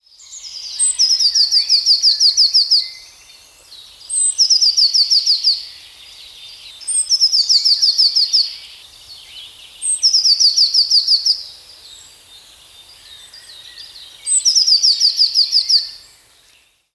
Hier kannst du dir Vogelstimmen anhören!
3-nr.-0125_blaumeise_gesang.wav